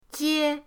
jie1.mp3